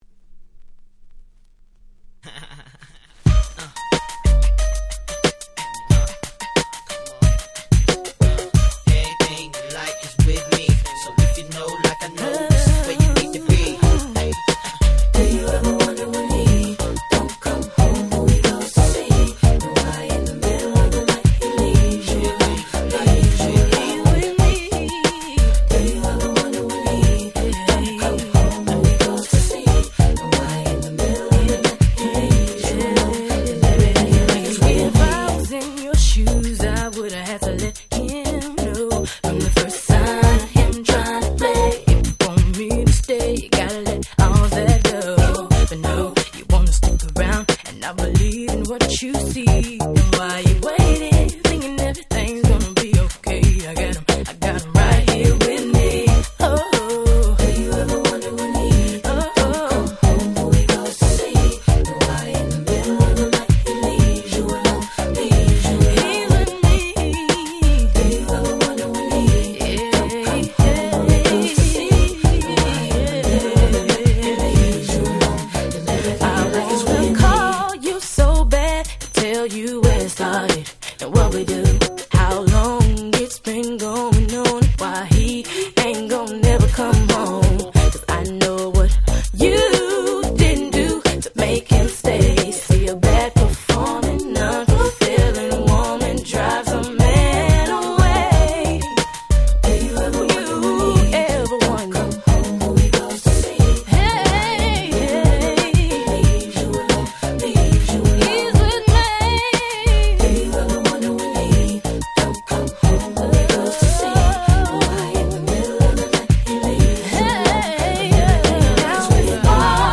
98' Smash Hit R&B !!